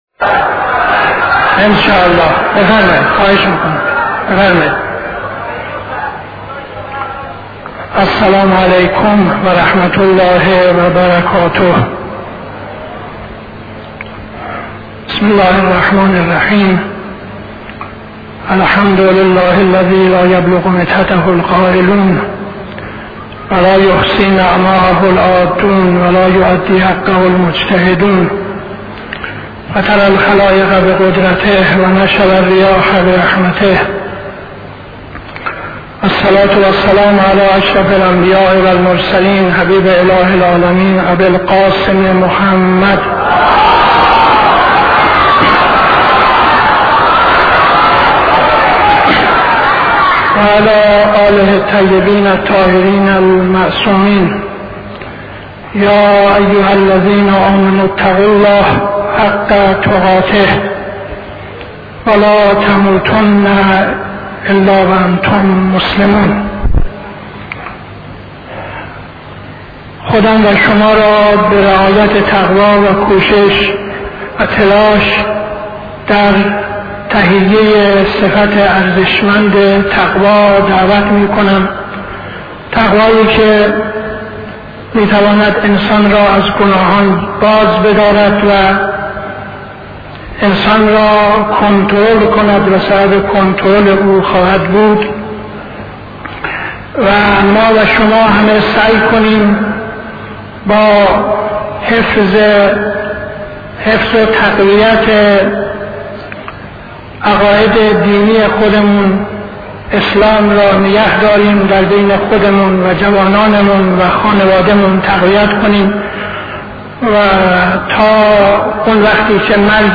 خطبه اول نماز جمعه 16-07-72